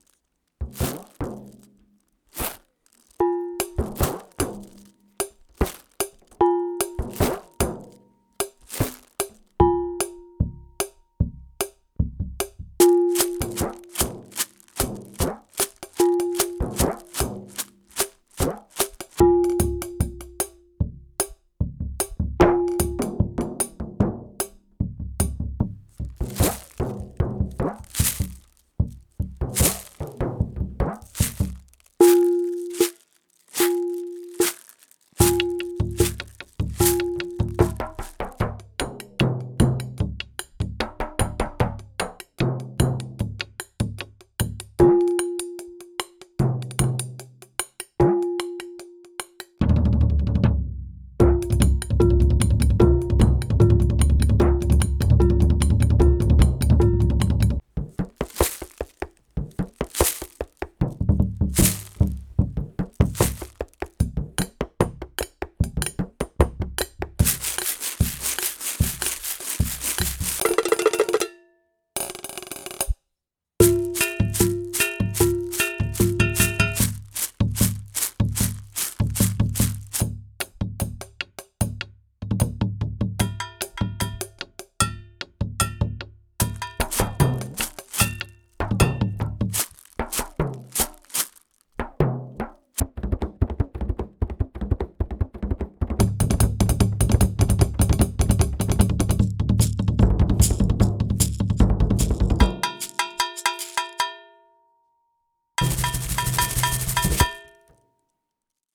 Genre:Percussion
中心となるのはアコースティックパーカッションの微妙な揺れと呼吸感であり、シェケレのリズム、明るいベル、フレームドラム、スネアのブラシ奏法、ハンドドラムなどを収録し、自然なダイナミクスと温かみのある開放的なサウンドで記録されています。
レコーディングは細部に焦点を当てており、シェイカーの柔らかな減衰、ブラシの息づかいのようなスウィープ、木に張られた皮を叩いたときの低く深い響きまでを捉えています。
デモサウンドはコチラ↓
157 Percussion Loops